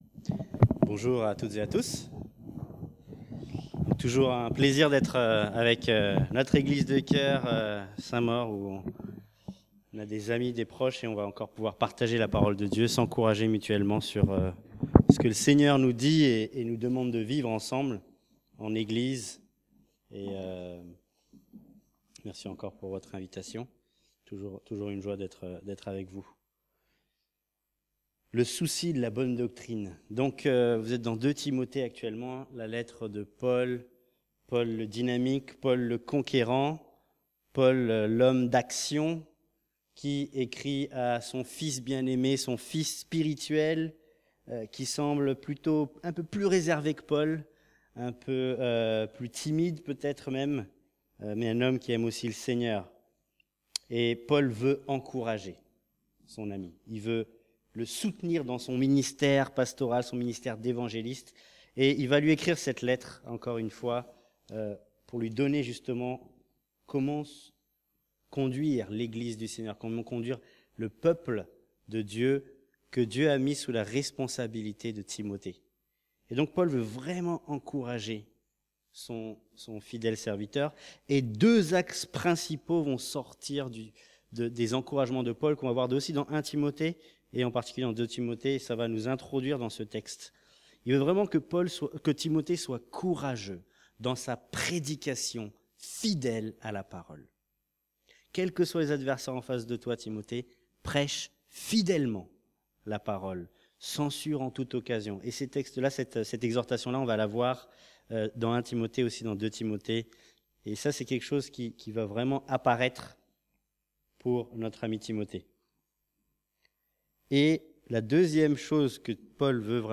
Prédication fidèle de la vérité et attitude qui glorifie Dieu. Mais avant, il avertit Timothée sur les conséquences d’une mauvaise doctrine.